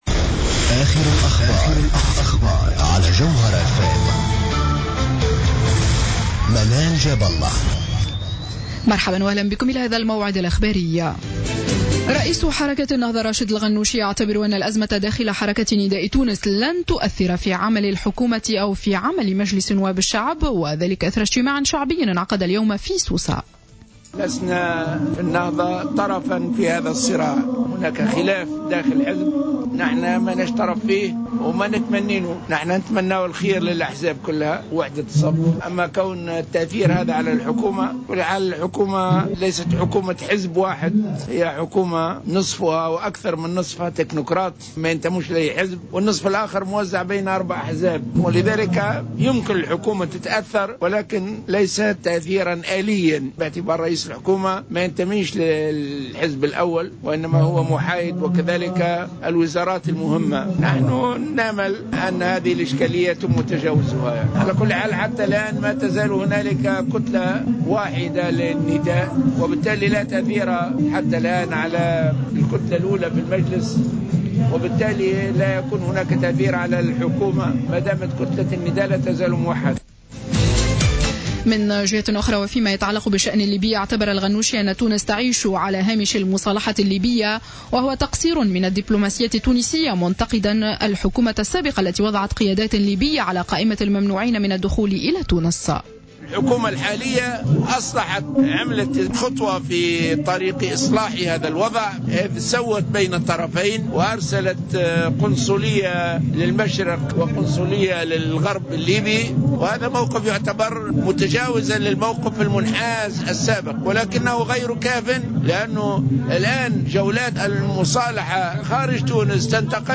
نشرة أخبار السابعة مساء ليوم الأحد 15 مارس 2015